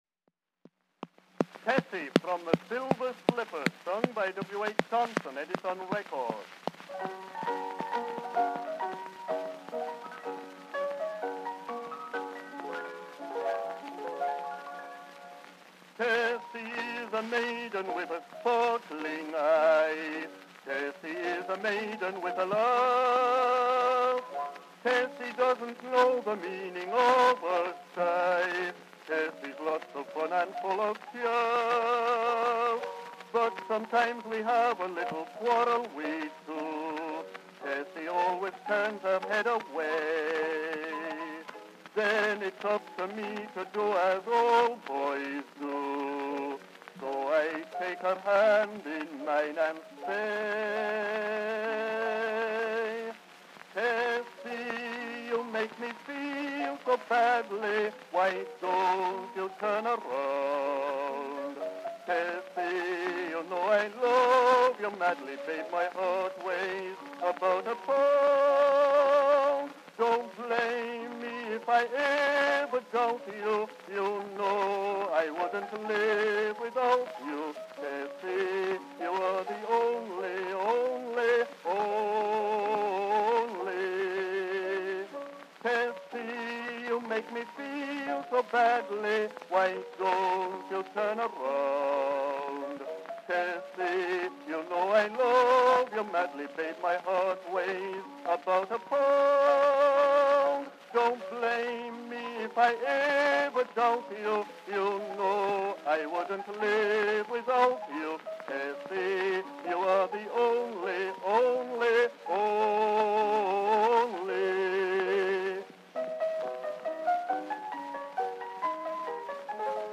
Cylinder 2941
Vocal selection.
Popular music—1901-1910.